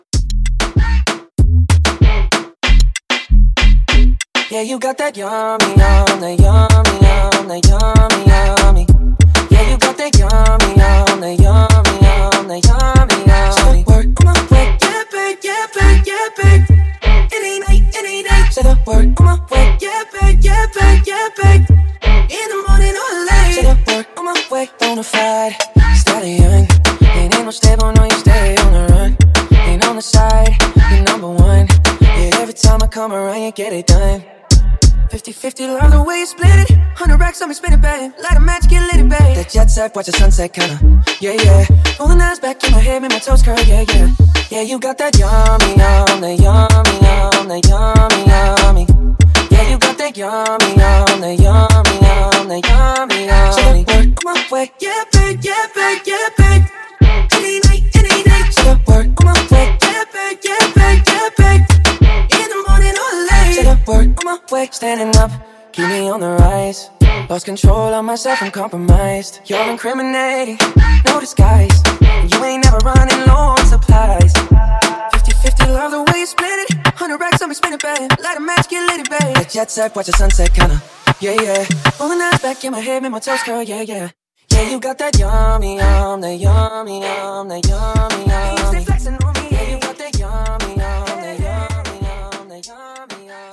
DANCE , MASHUPS , MOOMBAHTON 96 Clean